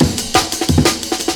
amen pt-3.wav